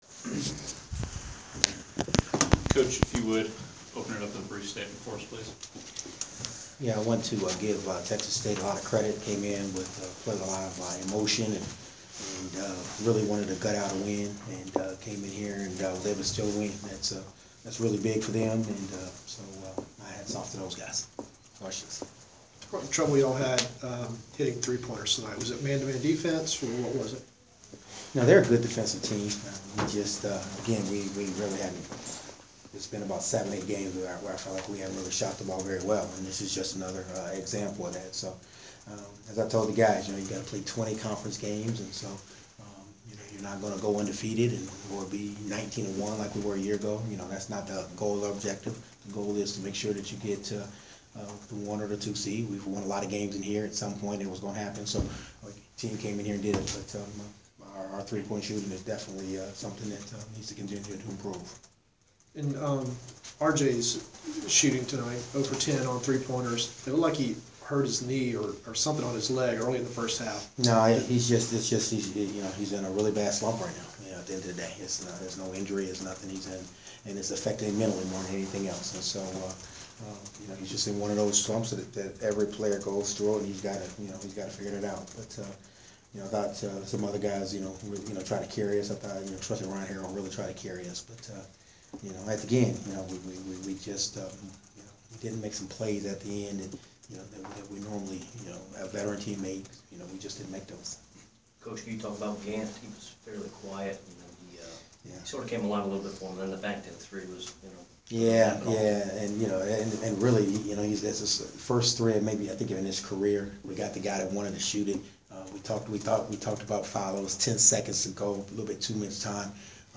Inside the Inquirer: Postgame presser with Georgia State men’s basketball coach Ron Hunter
We attended the postgame presser of Georgia State men’s basketball head coach Ron Hunter following his team’s 77-74 double overtime Sun Belt home loss to Texas State on Jan. 5.